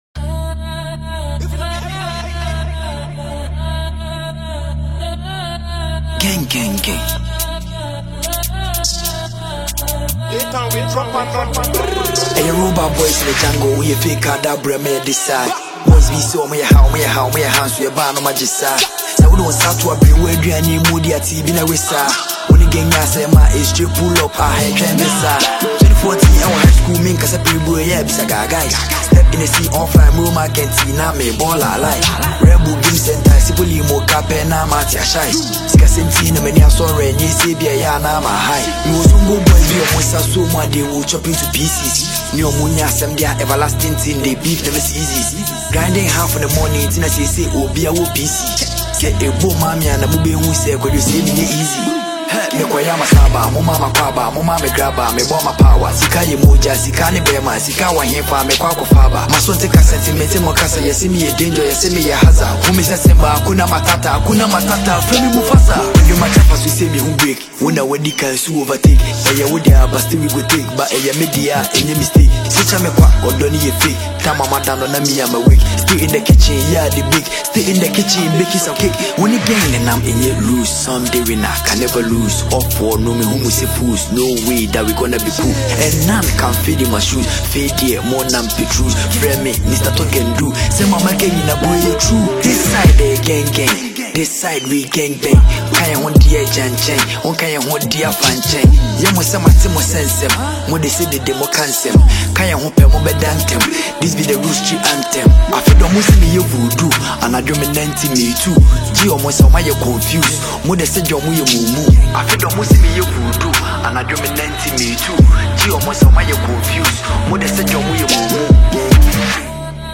Ghanaian drill